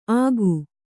♪ āgu